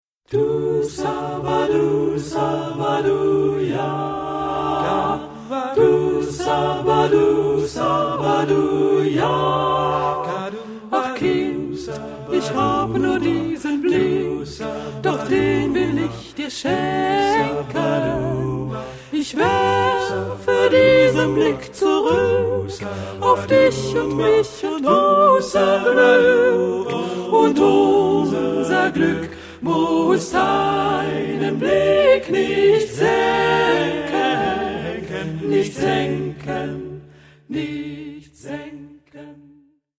Genre-Stil-Form: weltlich ; Choraljazz ; Liedsatz
Charakter des Stückes: melodiös ; jazzy ; humorvoll
Chorgattung: SATB  (4 gemischter Chor Stimmen )
Tonart(en): D-Dur